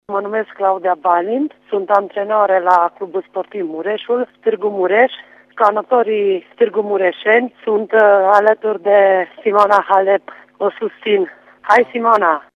O dovedesc mesajele oamenilor de sport din județele Mureș și Harghita: